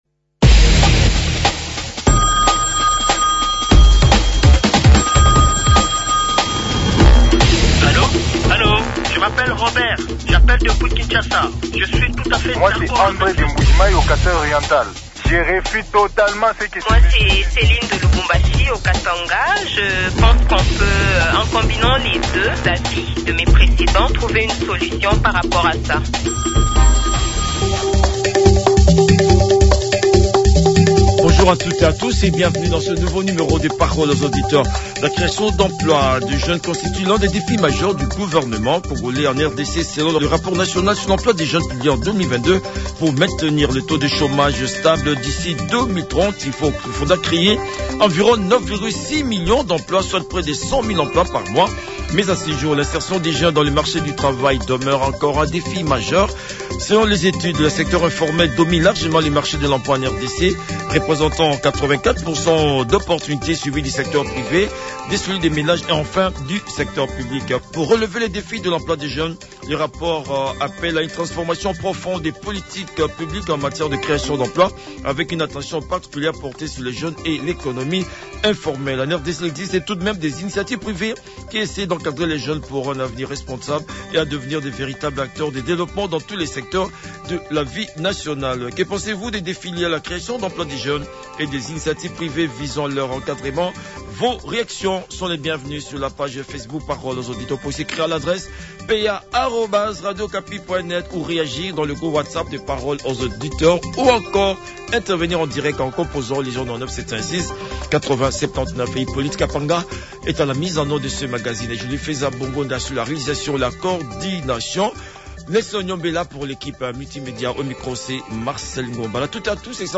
Les auditeurs ont échangé avec l'honorable Jethro Muyombi, député national et président de la sous-commission des affaires étrangères, coopération internationale et francophonie à l'Assemblée nationale.